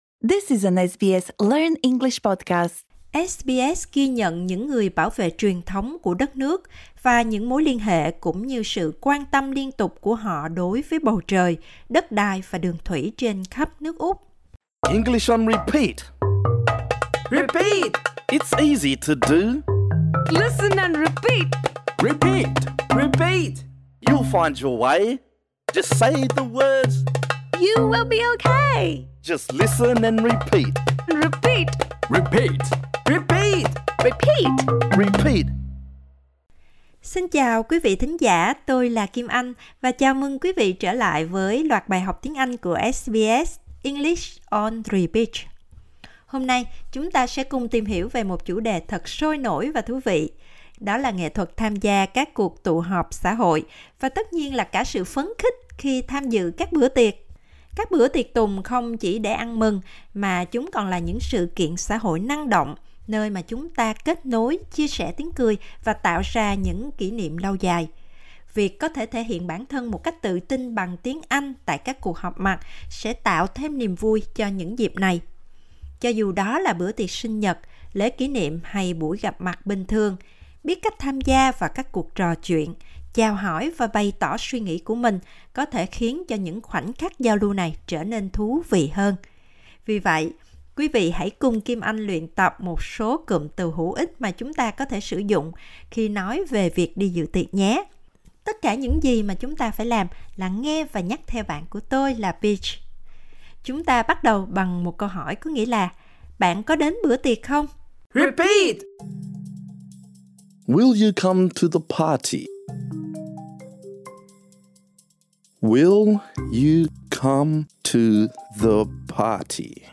Bài học này dành cho những người học ở trình độ dễ. Trong bài học này, chúng ta luyện nói những cụm từ sau: · Will you come to the party?